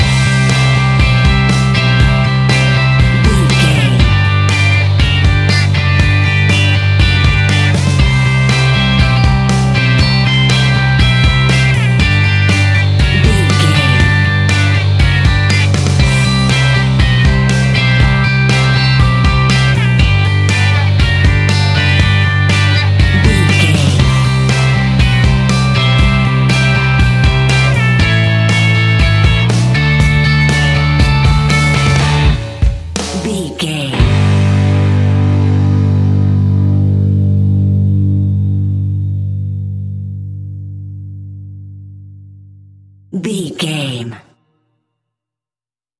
Ionian/Major
D
fun
energetic
uplifting
instrumentals
upbeat
uptempo
groovy
guitars
bass
drums
piano
organ